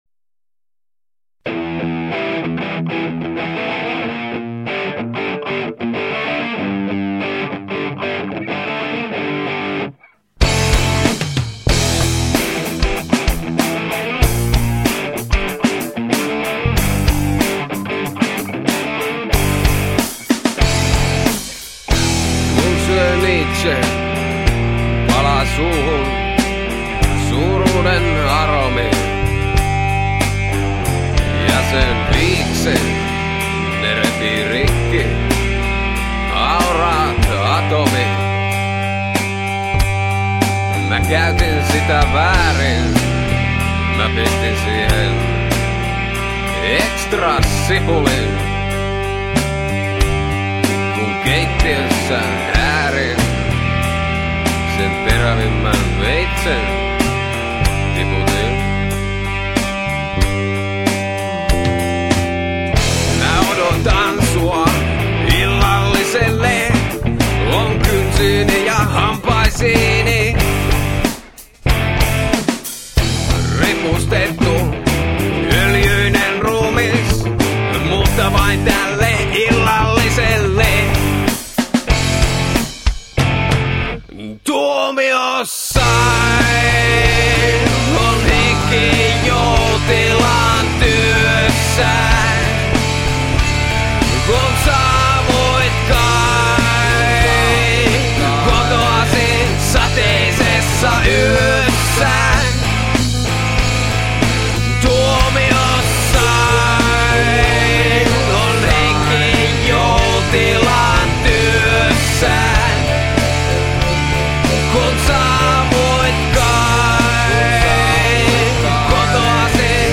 rock-yhtye
AO linkit ovat kaikki 128 kbps stereo mp3-tiedostoja.